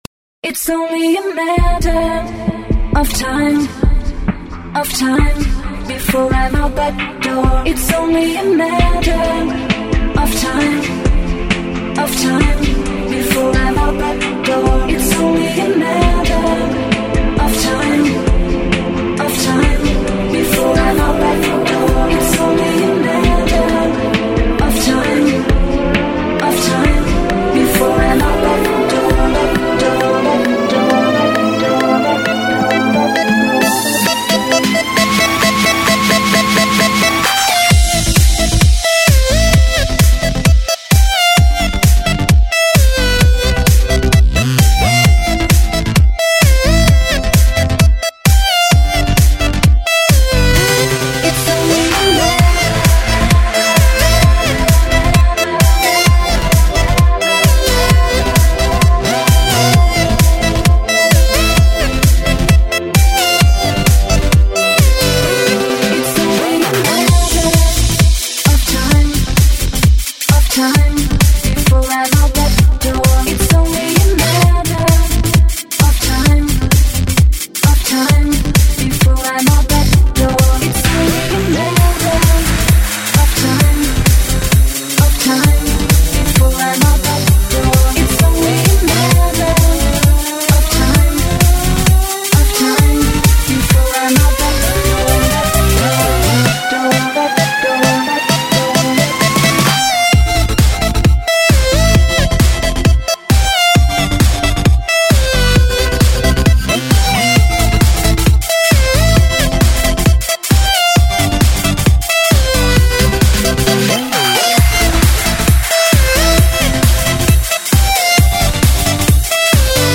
Данная песня находится в музыкальном жанре Клубная музыка.